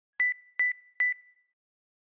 Low Battery.mp3